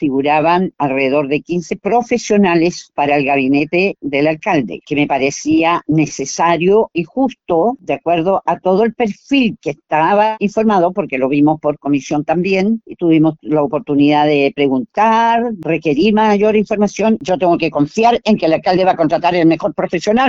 concejala-mirta-vega.mp3